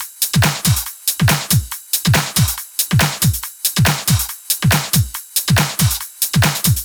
VFH2 140BPM Lectrotrance Kit 3.wav